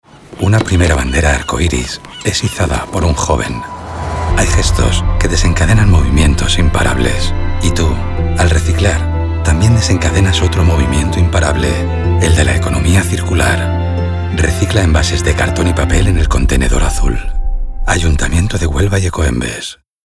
Cuña Radio Envase Cartón - Hª Bandera LGTBIQ+ | Ecoembes